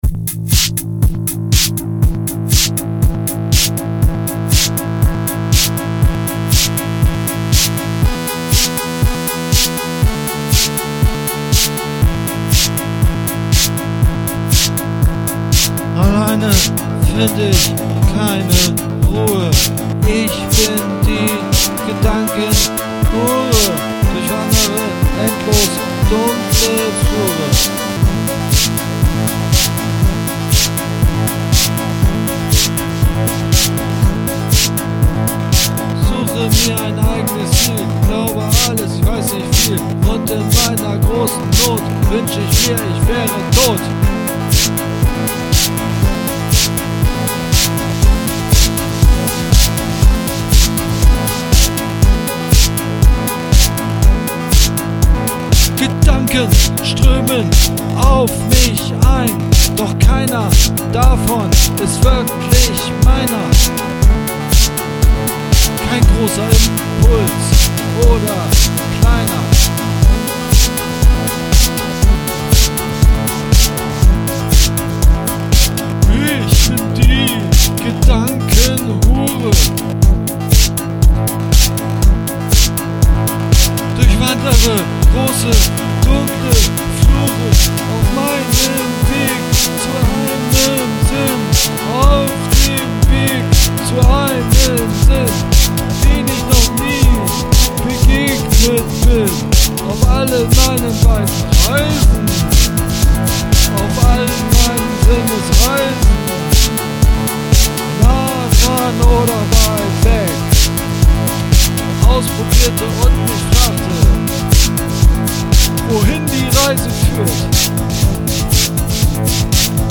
Wer fühlen will, muss hören: Demoversion „Gedankenhure“ / oPhone